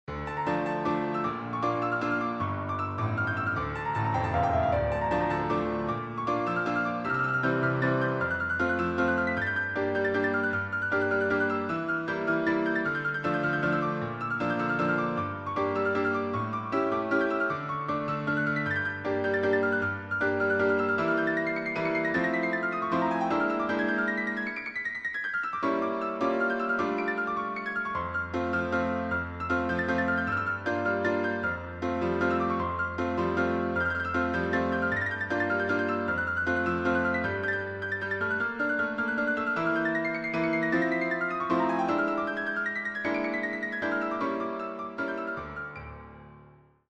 melodikası ilə cazın cazibədarlığını özündə birləşdirərək